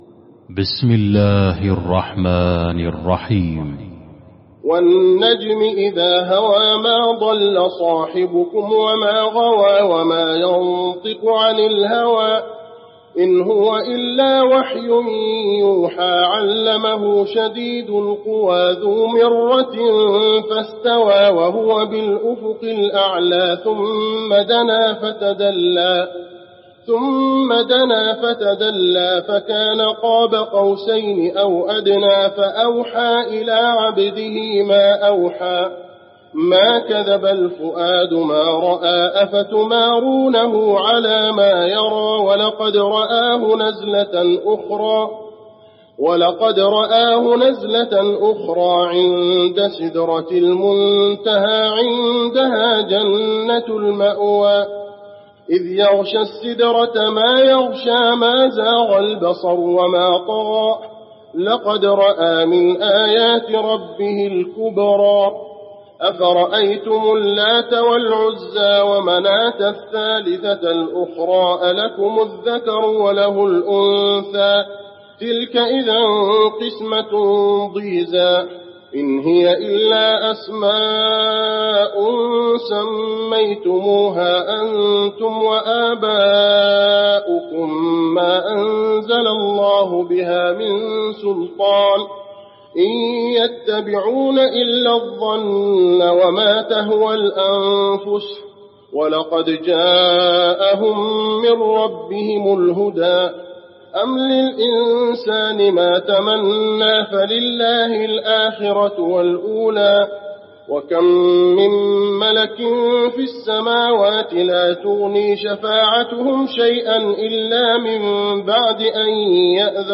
المكان: المسجد النبوي النجم